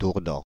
Dourdan (French pronunciation: [duʁdɑ̃]